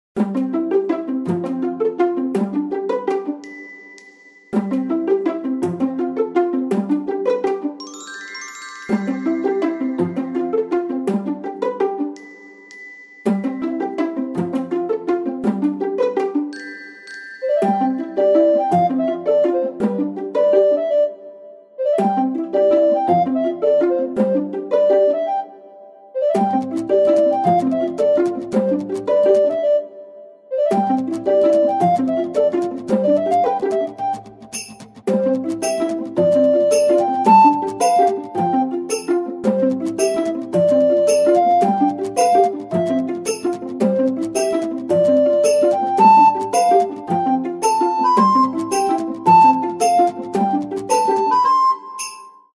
フリーBGM その他
mushroom_forestLOOP OGG